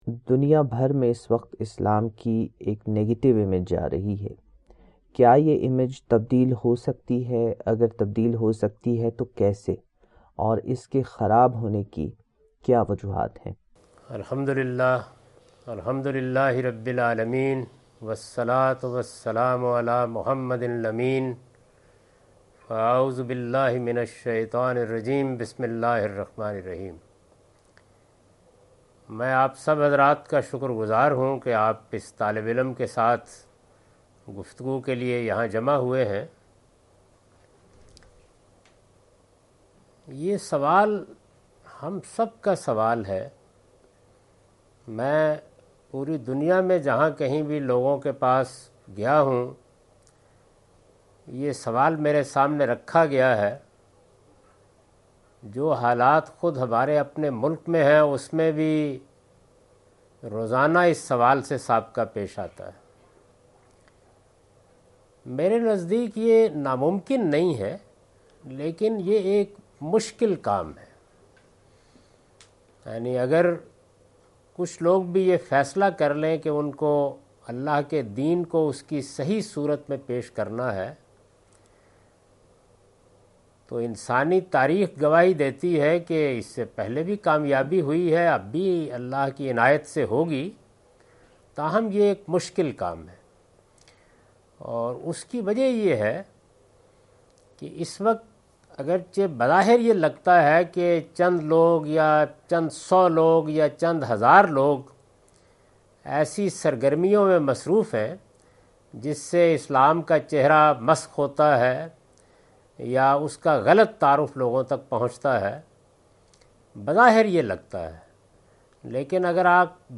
In this program Javed Ahmad Ghamidi answers miscellaneous questions asked by teachers and students in JNU, Dehli, India on 16th February, 2017.